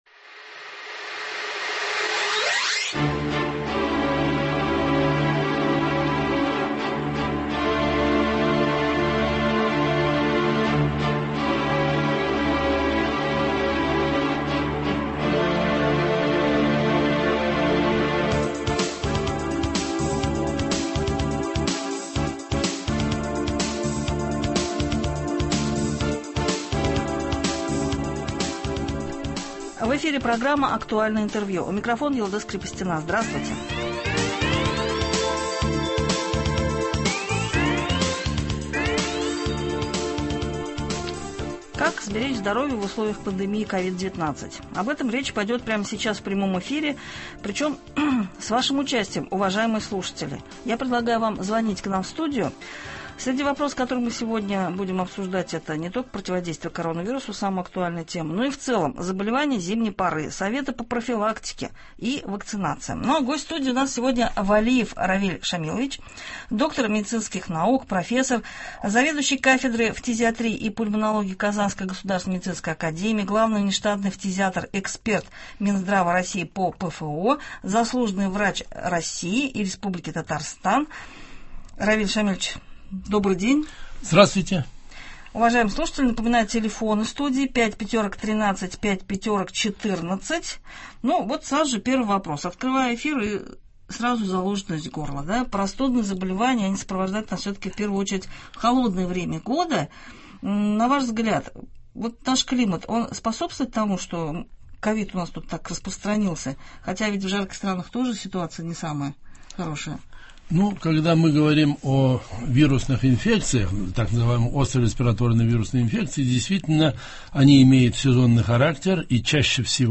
Актуальное интервью (03.02.21)
Как сберечь здоровье в условиях пандемии Ковид-19? Об этом речь шла в прямом эфире. С участием слушателей обсудили острые вопросы здравоохранения .